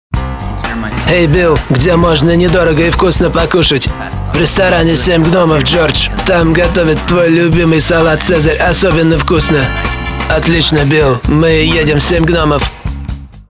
Делаю озвучку (пародия) голосом переводчика из 90-х.)
Тракт: Микрофон Shure KSM 44, звуковая карта Focuserite Saffire 6